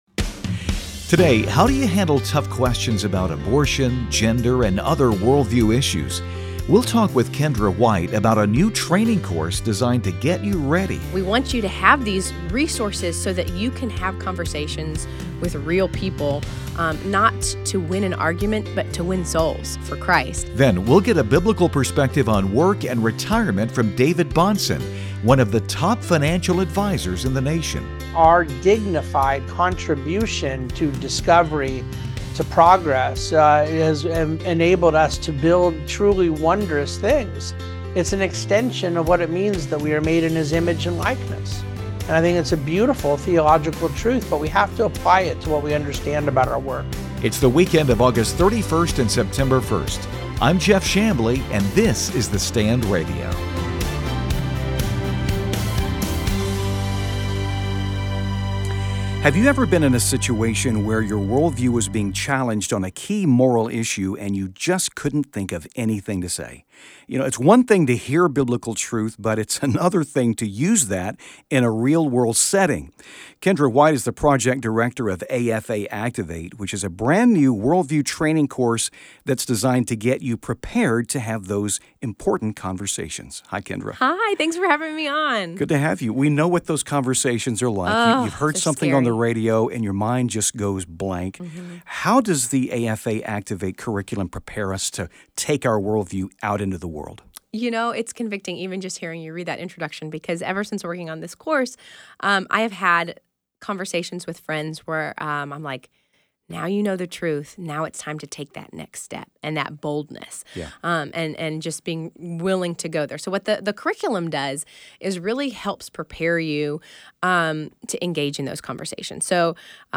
Through interviews with authors, writers, and newsmakers